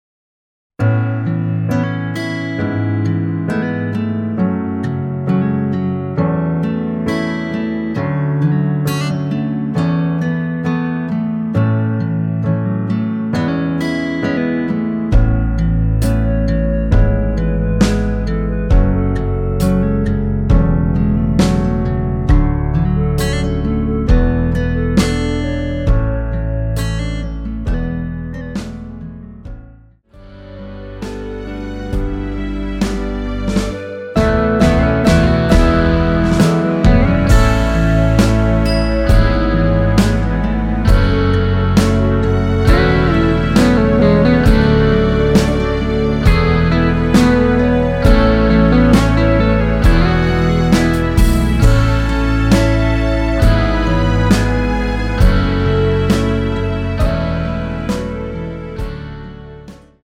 원키에서 (+1)올린 멜로디 포함된 MR입니다.(미리듣기 참조)
노래방에서 노래를 부르실때 노래 부분에 가이드 멜로디가 따라 나와서
앞부분30초, 뒷부분30초씩 편집해서 올려 드리고 있습니다.
중간에 음이 끈어지고 다시 나오는 이유는